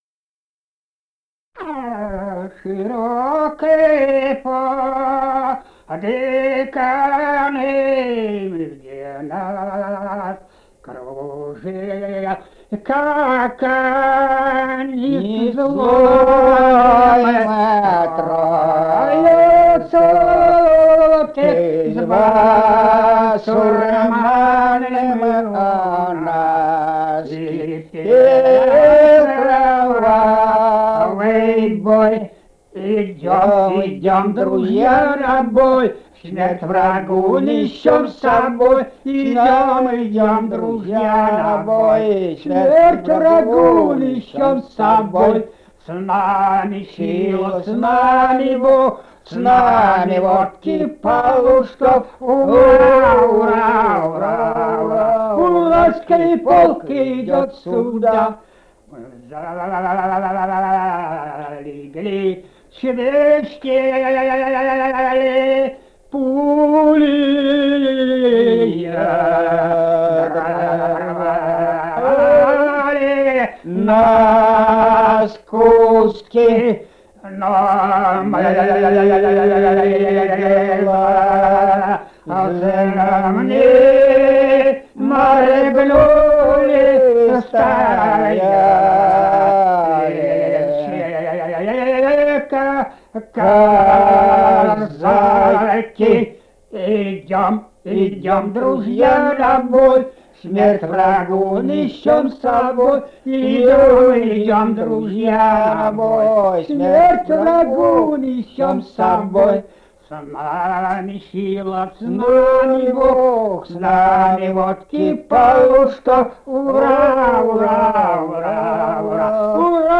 Этномузыкологические исследования и полевые материалы
Казахстан, г. Уральск, 1972 г. И1312-25